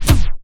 HISS KICK.wav